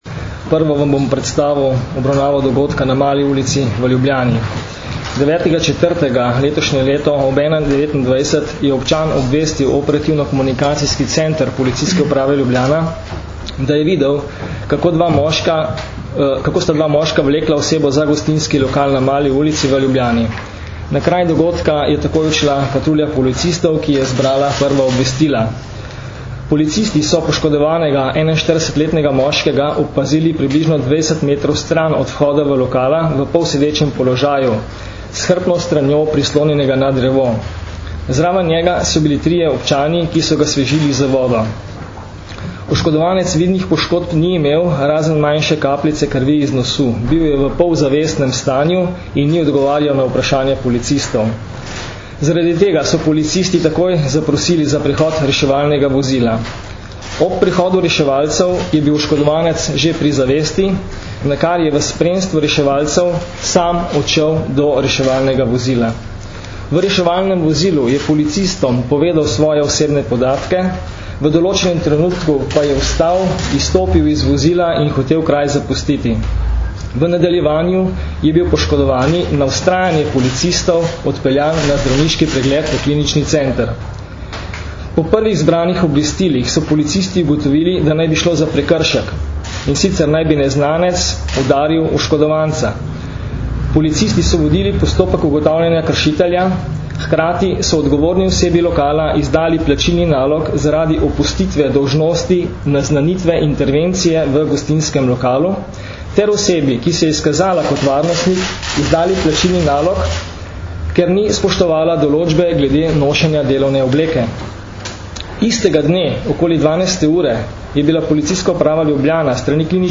Zvočni posnetek izjave mag. Stanislava Vrečarja, direktorja PU Ljubljana (mp3)